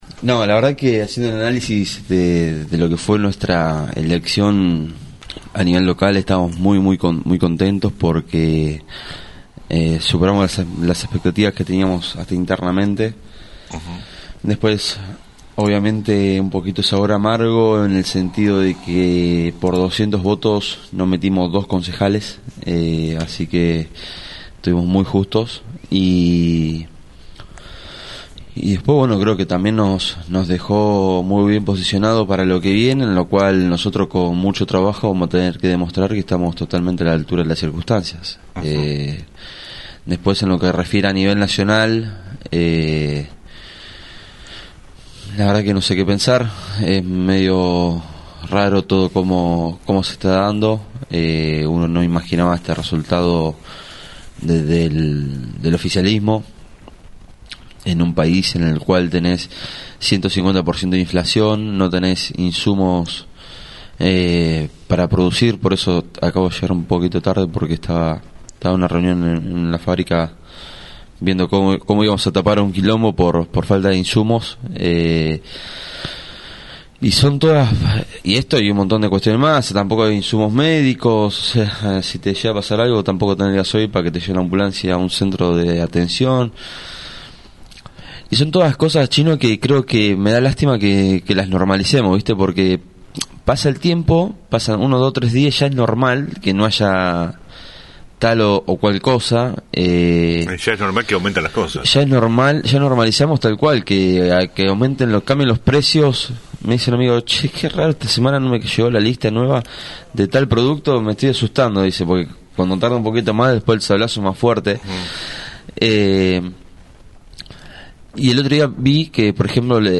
estuvo en los estudios de la radio